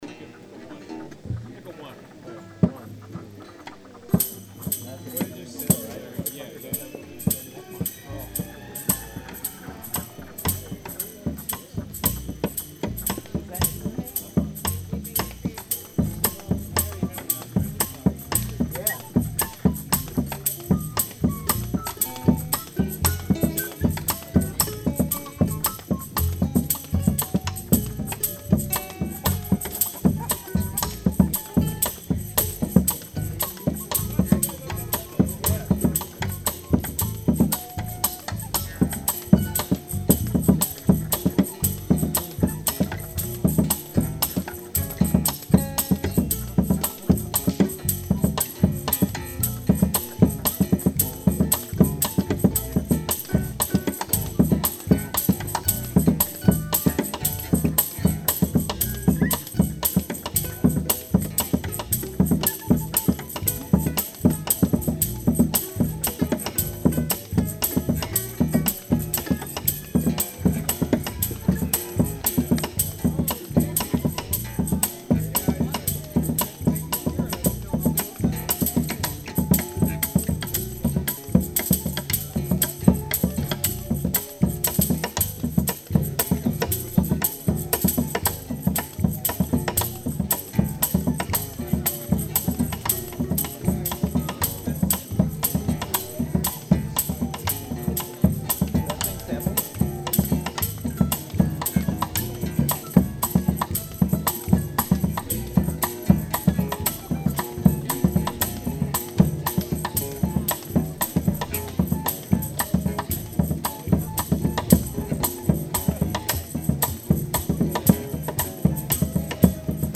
Location: Lake Clara